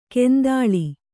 ♪ kendāḷi